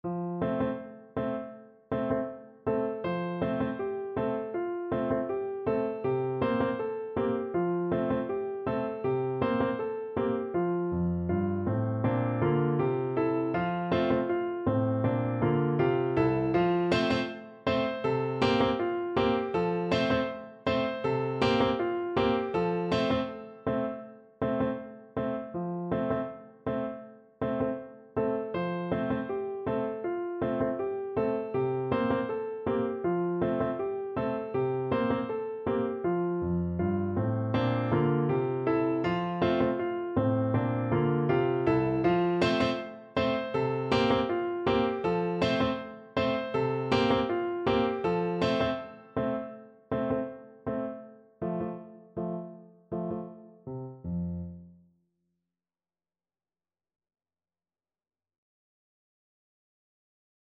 No parts available for this pieces as it is for solo piano.
2/4 (View more 2/4 Music)
F major (Sounding Pitch) (View more F major Music for Piano )
Moderately slow =c.80
Piano  (View more Intermediate Piano Music)
Traditional (View more Traditional Piano Music)
cublak_cublak_suweng_PNO.mp3